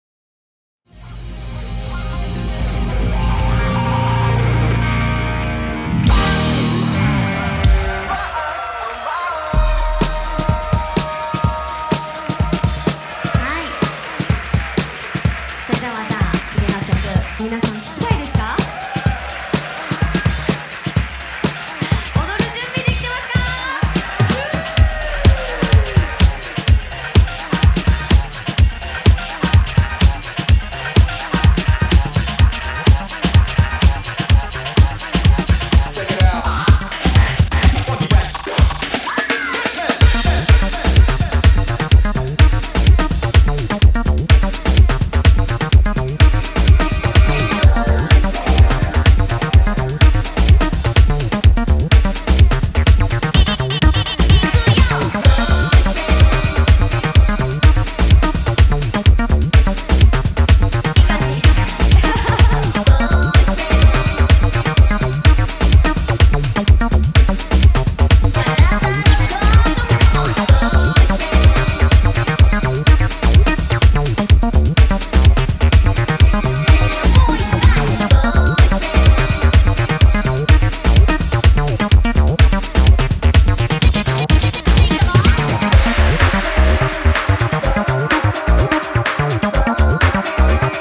ハウシーなダンサブル・トラックが完成！